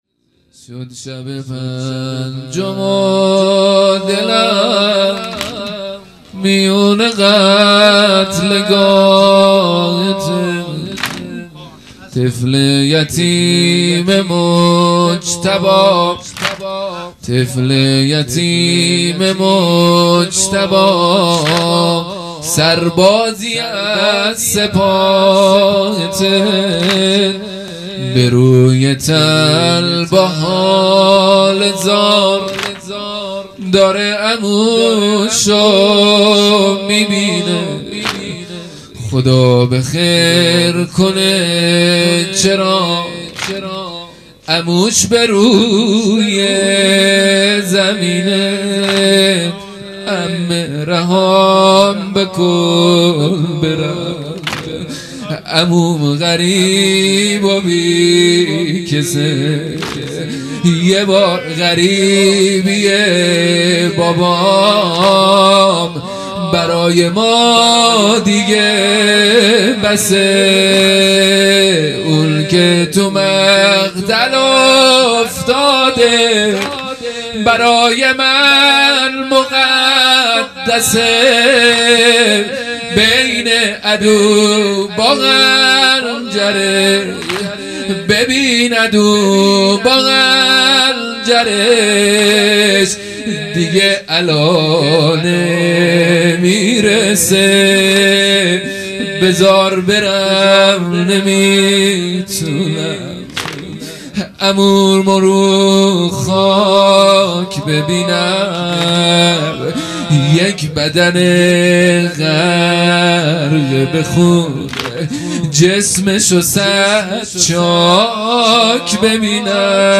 هیئت مکتب الزهرا(س)دارالعباده یزد
1 0 واحد | شد شب پنجم و دلم میون قتلگاهته مداح